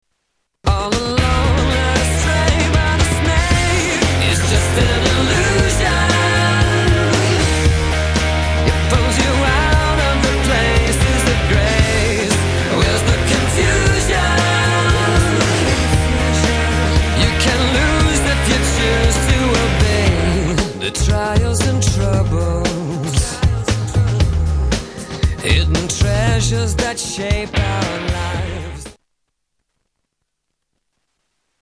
STYLE: Pop
guitar crunching